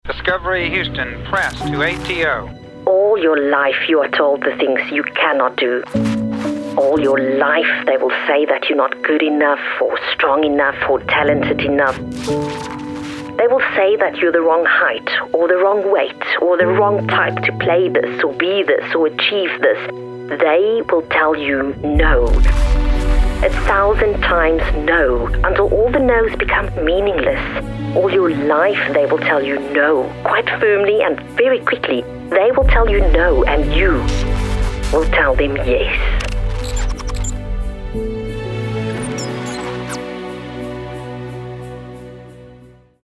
comforting, energetic, gentle, inviting, warm
Vocal Age:
My demo reels
English-emotive-demo.mp3